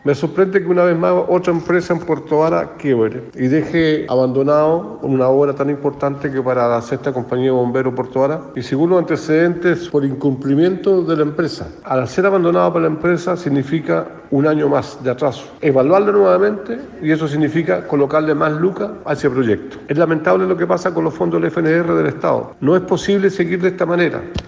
El consejero regional Manuel Rivera, expresó que no pueden seguir ocurriendo este tipo de situaciones.